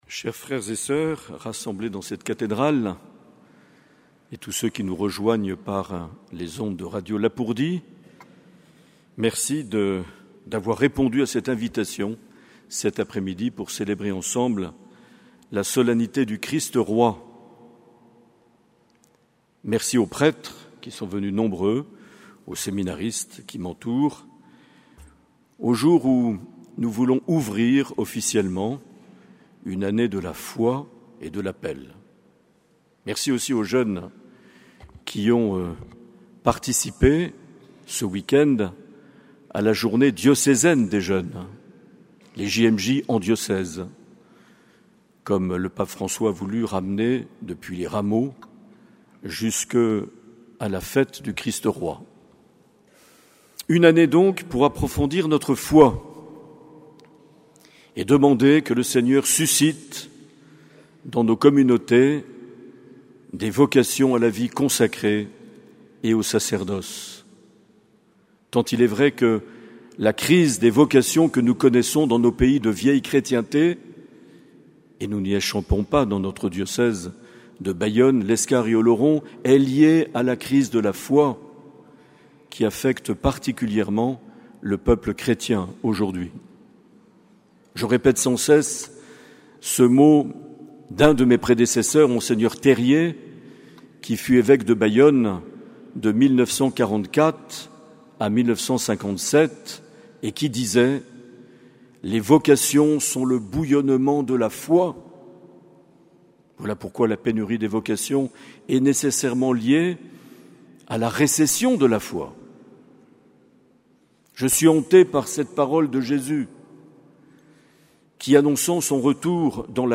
Accueil \ Emissions \ Vie de l’Eglise \ Evêque \ Les Homélies \ 26 novembre 2023 - Messe de lancement de l’Année de la foi et de (...)
Une émission présentée par Monseigneur Marc Aillet